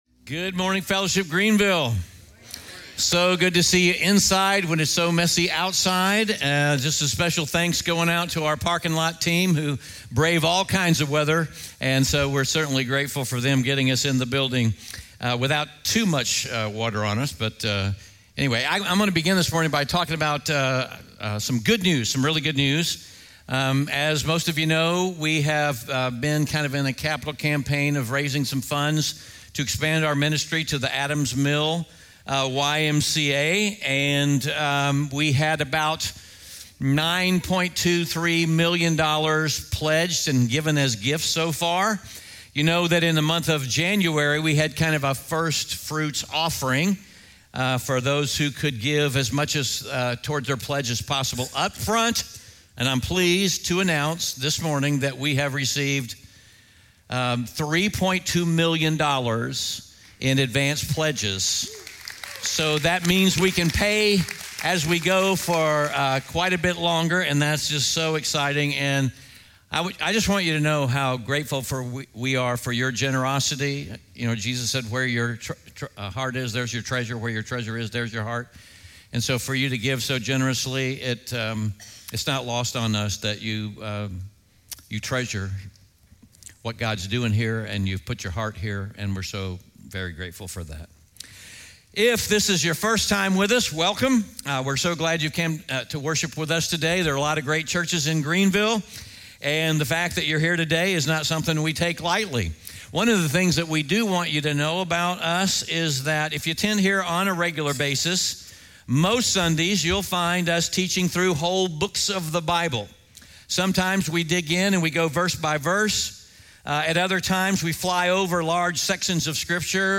SERMON SCREENSHOTS & KEY POINTS